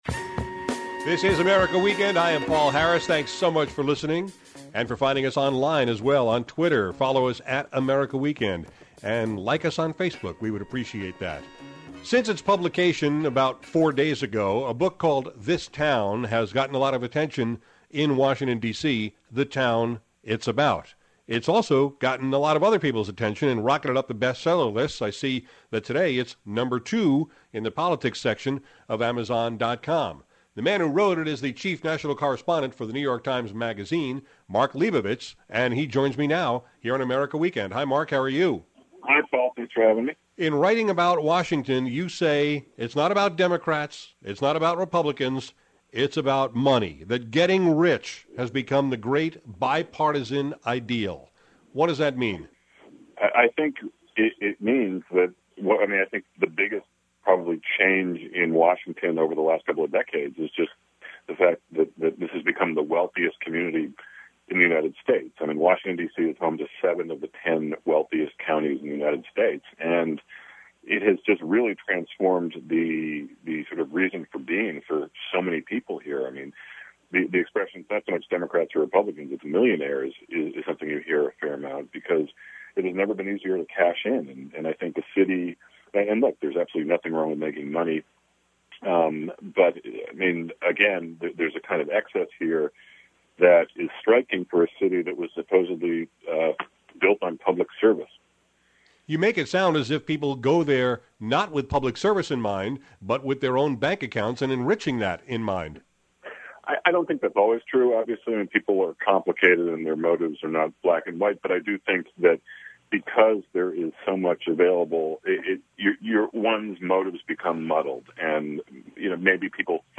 Mark Leibovich is making waves with his inside-Washington book, “This Town,” so I invited him to talk about it on America Weekend today. He explained how DC isn’t about Democrats and Republicans, it’s about millionaires, and how money is at the root of everything. We talked about “outsiders” who have always promised to change Washington but never have, why the White House Correspondents Dinner represents everything that’s wrong with DC, and how punditry has replaced reporting in recent years.